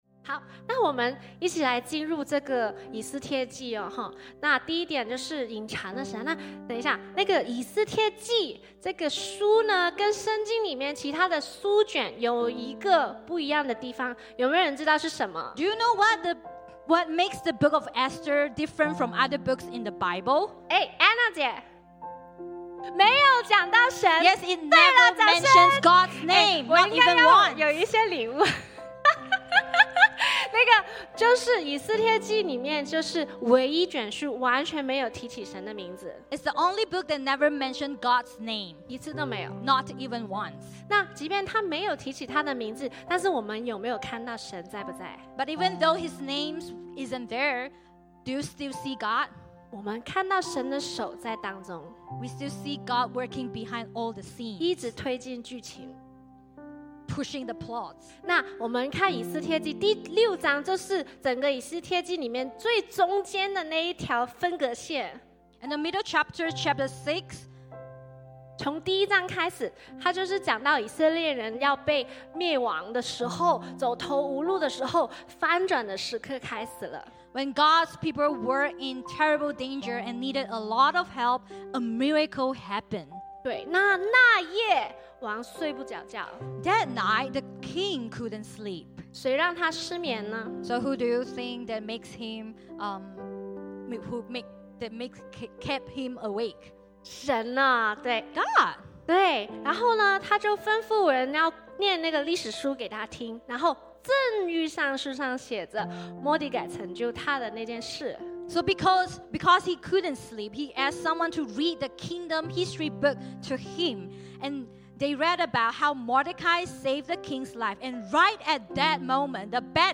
講道者首先指出，《以斯帖記》是聖經中唯一一卷*完全沒有提到「神」之名*的書卷。然而，雖然神的名字不在其中，神的手卻一直在幕後推動劇情。